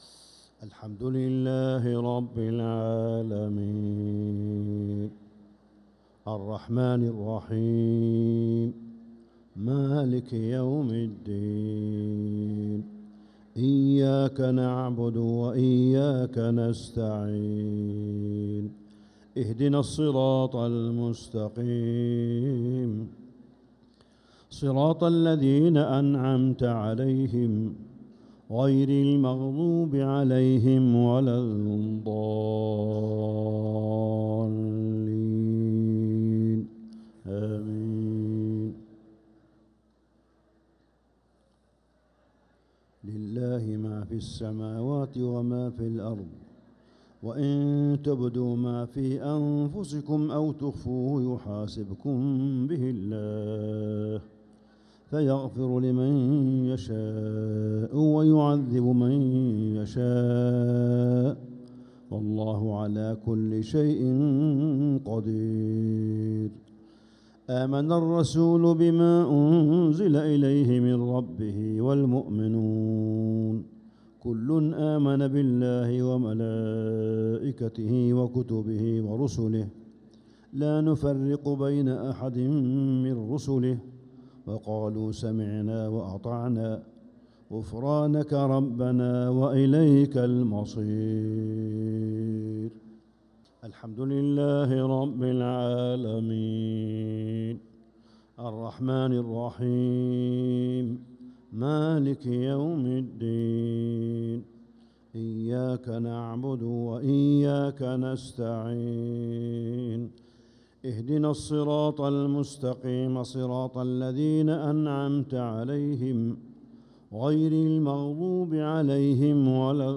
عشاء الخميس 6-9-1446هـ خواتيم سورة البقرة 284-286 | Isha prayer from Surat al-Baqarah 6-3-2025 > 1446 🕋 > الفروض - تلاوات الحرمين